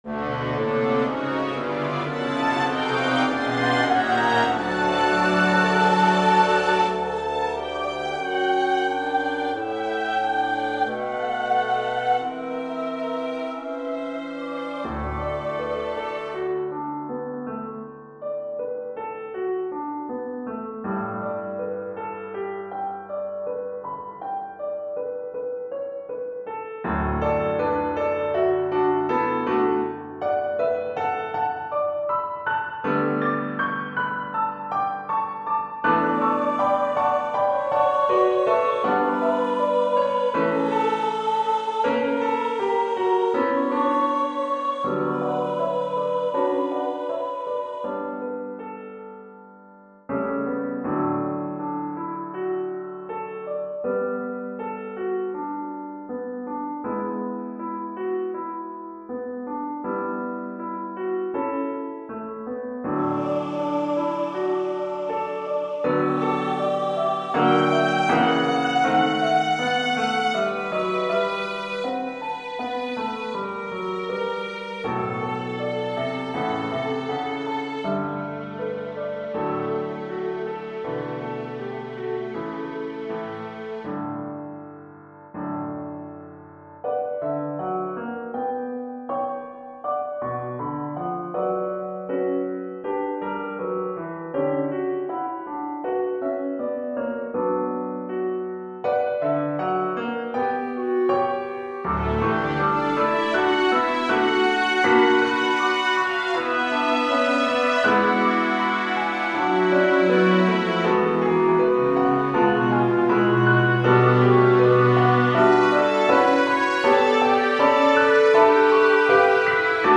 Hello Everyone, I have written a short piano piece, Romance today, for a lady I love.